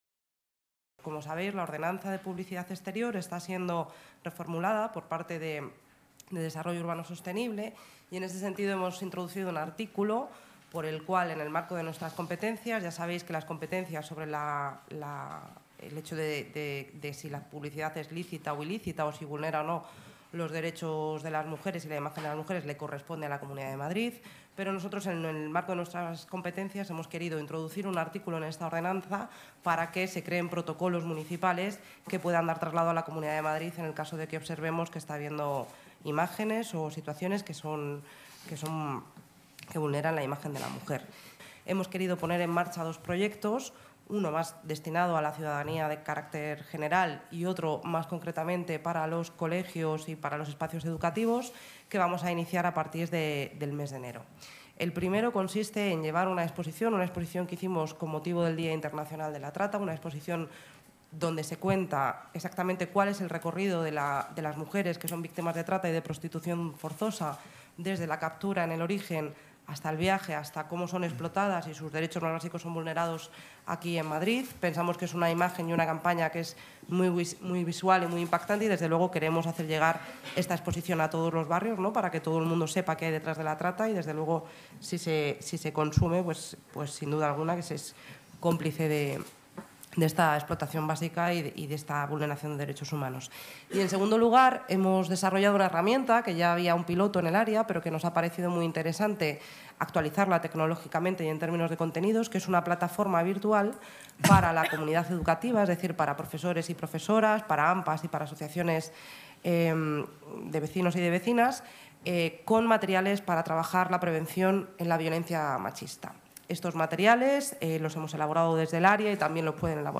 La delegada de Políticas de Género y Diversidad, Celia Mayer, explica que la nueva ordenanza de publicidad incorporará un artículo relacionado con este tipo de publicidad: